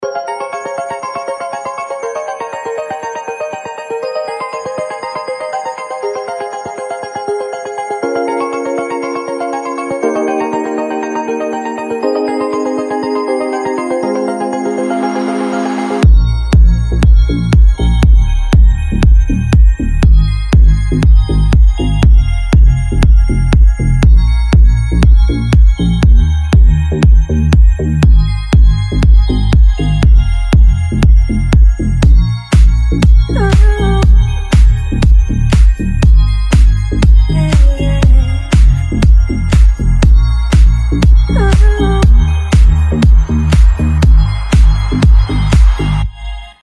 • Качество: 320, Stereo
deep house
dance
без слов
club
колокольчики